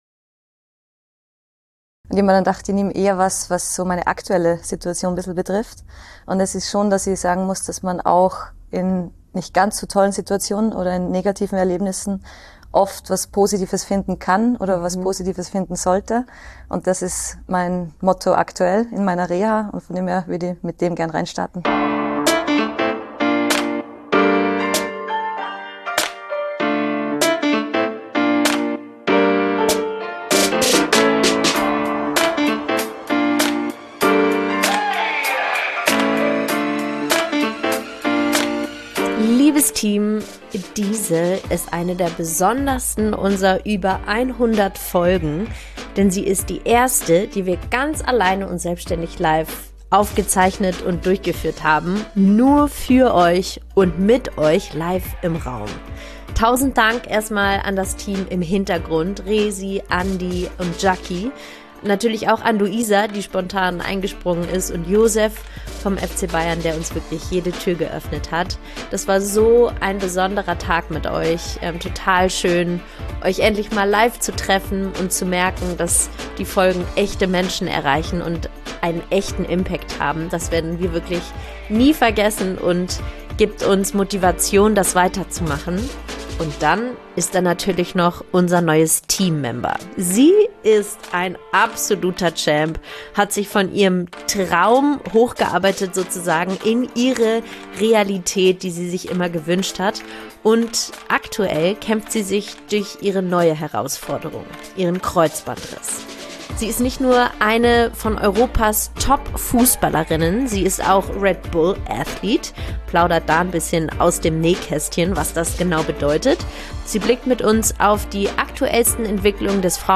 Liebes Team, diese ist eine der besondersten unser über 100 Folgen - denn sie ist die erste, die wir ganz alleine und selbständig live aufgezeichnet haben - nur für euch und mit euch im Raum.